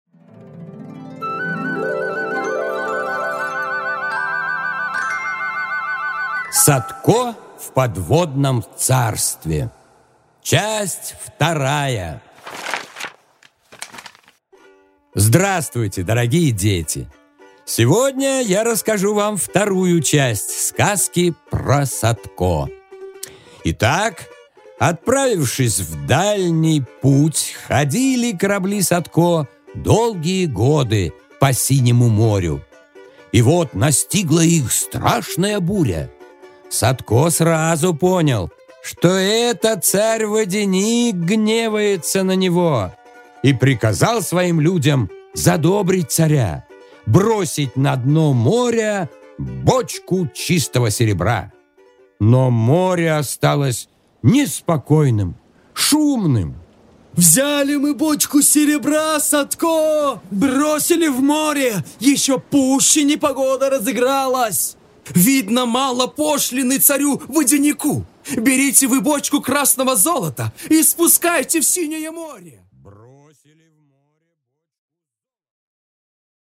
Аудиокнига Садко в подводном царстве 2-часть | Библиотека аудиокниг